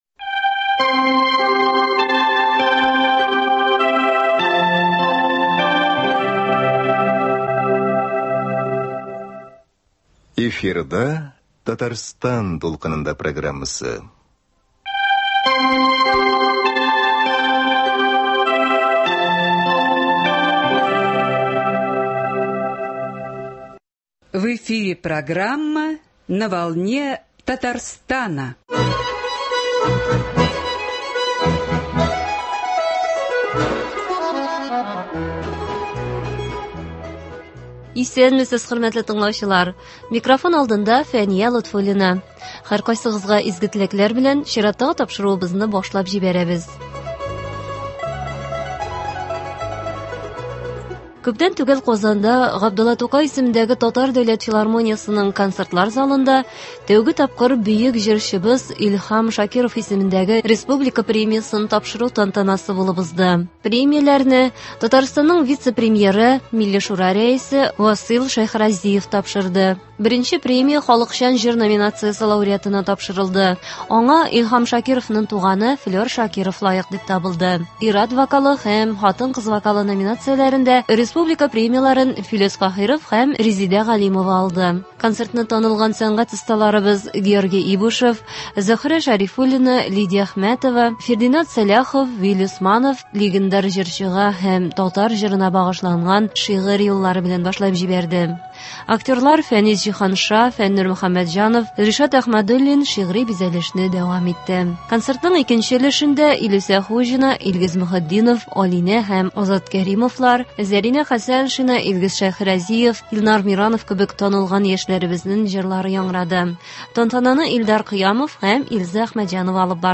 Бүген игътибарыгызга әлеге кичәнең берникадәр кыскартылган радиовариантын тәкъдим итәбез.